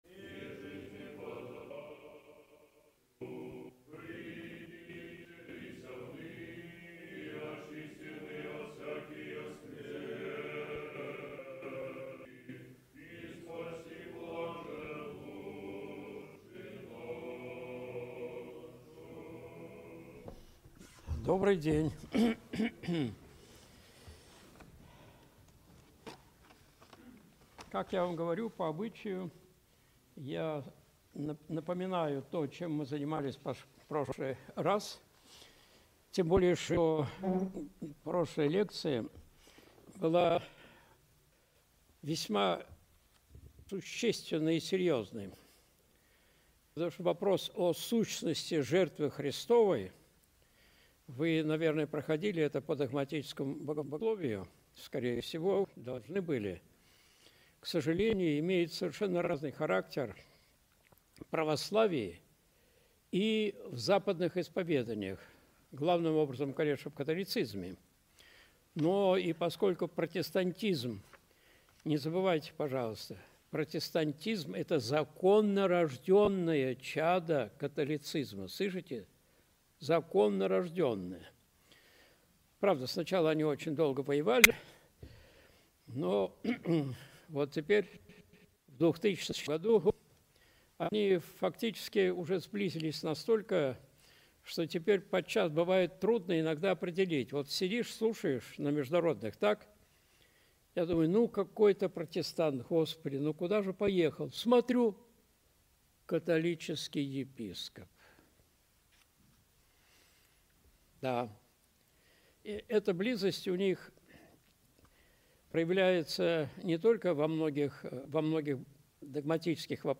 Видеолекции протоиерея Алексея Осипова